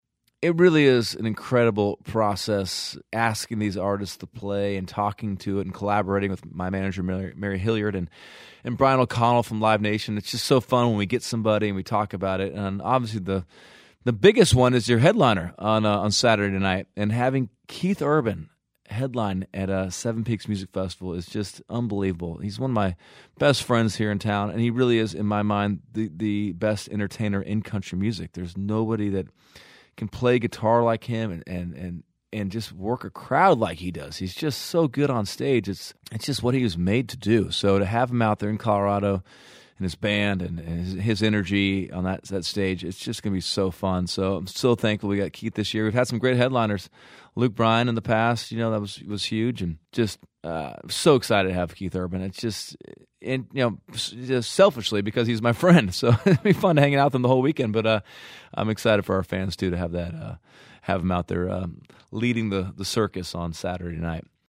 Audio / Dierks Bentley talks about Seven Peaks Festival and having Keith Urban headline the Saturday lineup.